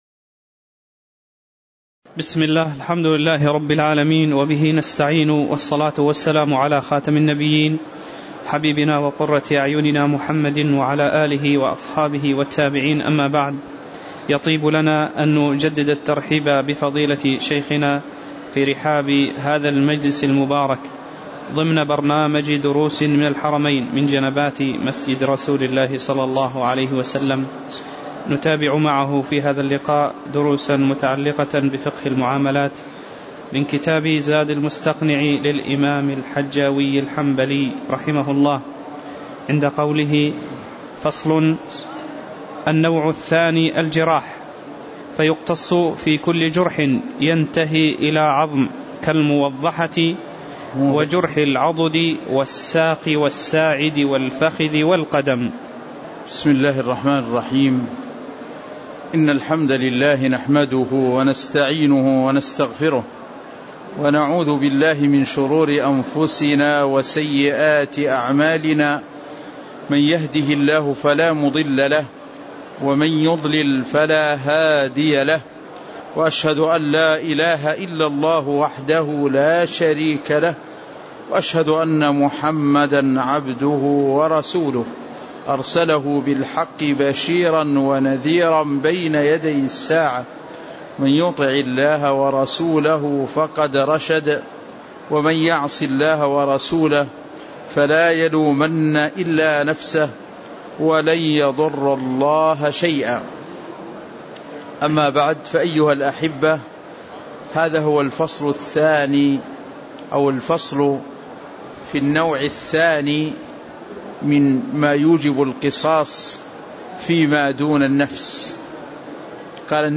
تاريخ النشر ١٣ ربيع الأول ١٤٣٨ هـ المكان: المسجد النبوي الشيخ